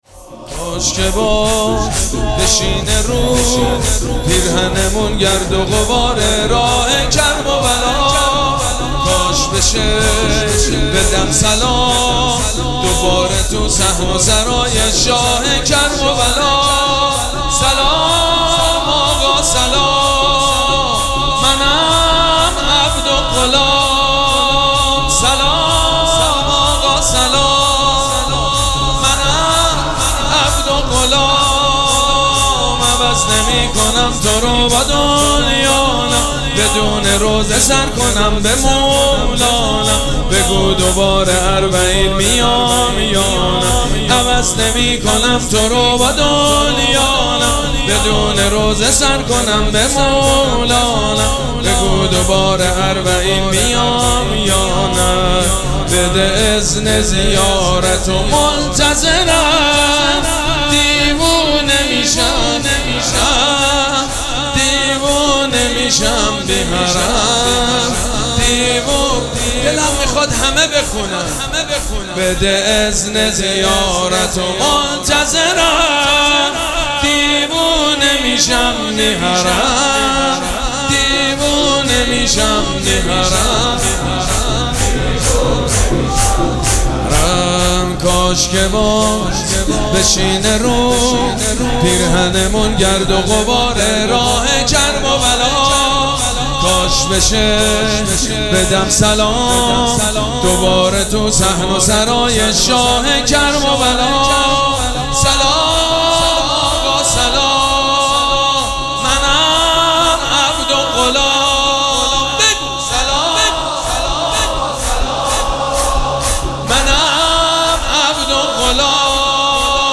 مراسم عزاداری شب شهادت حضرت رقیه سلام الله علیها
شور
حاج سید مجید بنی فاطمه
مراسم عزاداری شهادت امام حسن (ع) و حضرت رقیه (س)